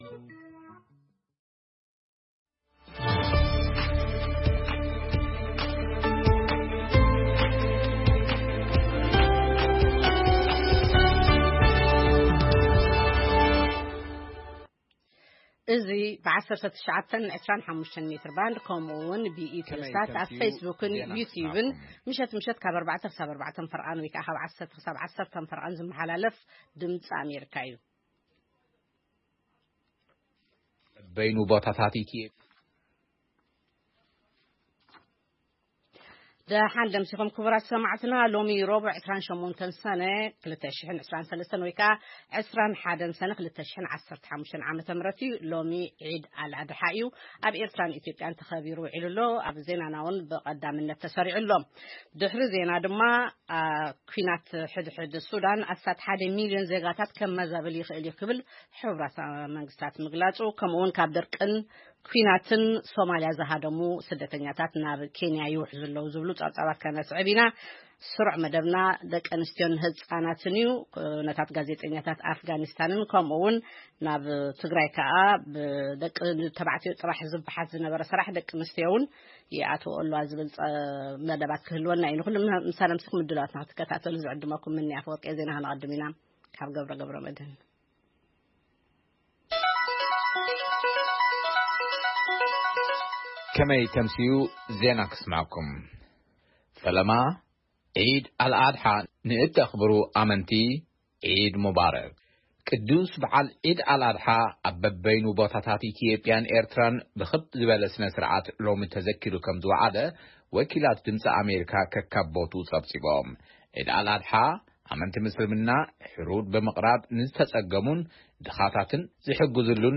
ፈነወ ድምጺ ኣመሪካ ቋንቋ ትግርኛ 28 ሰነ 2023 ዜና ጸብጻብ ኩነታት ካብ ሱዳን ናብ ጫድ ዝተሰደዱን ኣብ መዓስከር ስደተኛታት ኬንያ ዘለው ስደተኛታት ንኣብነታዊያን ደቂ ኣንስትዮ ዝድህስስ መደብ የጠቓልል